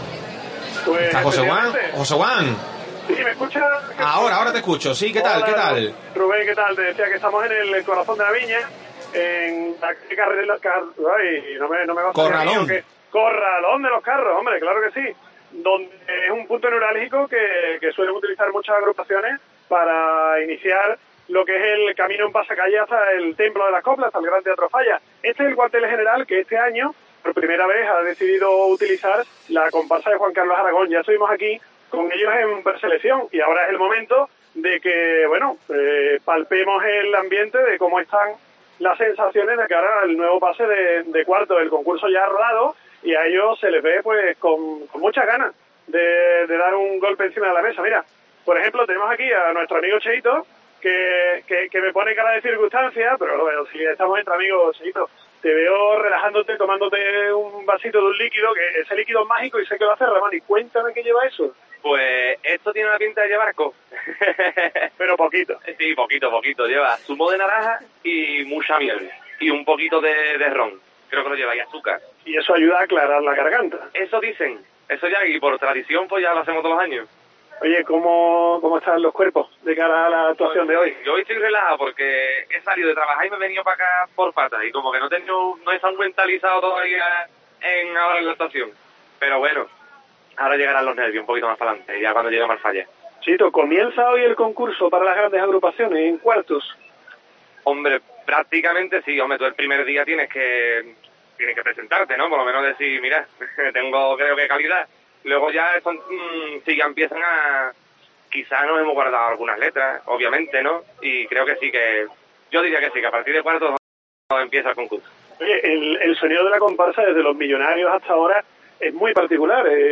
AUDIO: Nos colamos en el local donde se prepara la comparsa 'La Gaditaníssima'. Hablamos con los intregrantes y el propio Juan Carlos Aragón